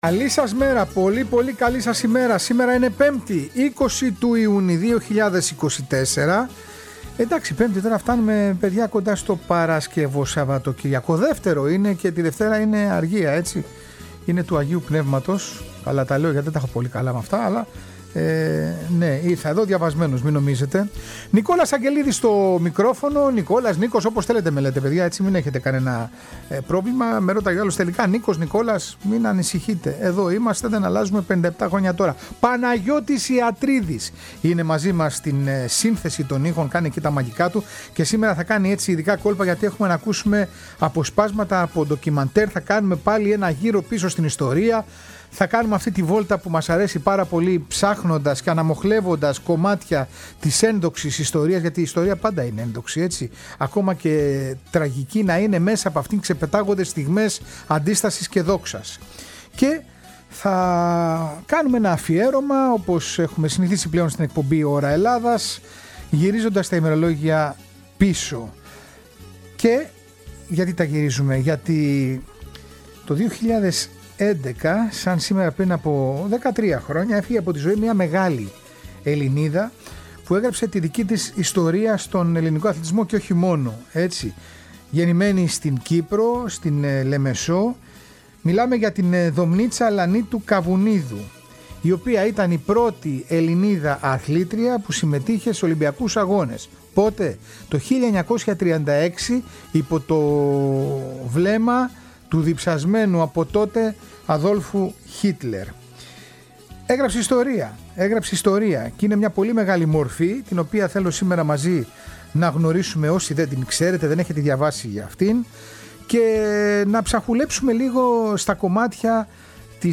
Μιλάει η ίδια για τη ζωή της μέσα από αφιερώματα – ντοκιμαντέρ. Αφηγείται τον πανικό του Χίτλερ μπροστά στη νίκη του Τζέσε Όουενς, αναφέρεται στις δυσκολίες για τις γυναίκες της εποχής, στον αγαπημένη της αθλητισμό που υπηρέτησε μέχρι το τέλος της ζωής της στα 97 της χρόνια…